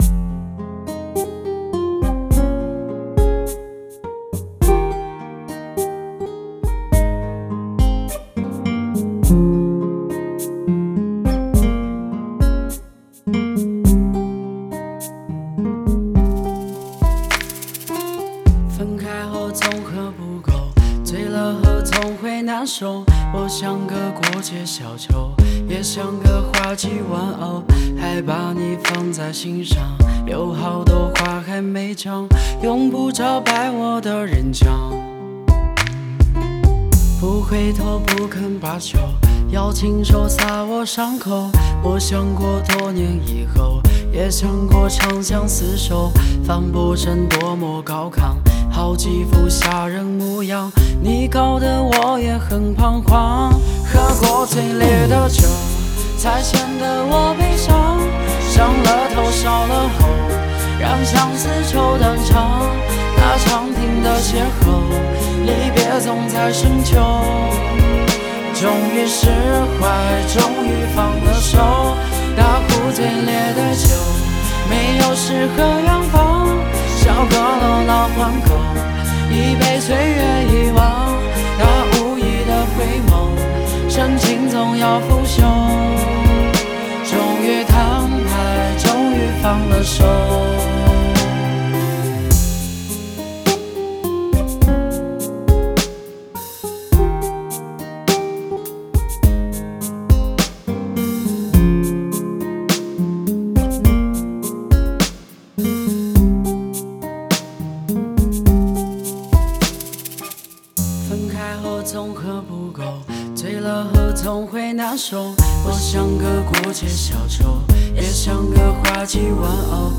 Ps：在线试听为压缩音质节选，体验无损音质请下载完整版
人声录音室：Hot Music Studio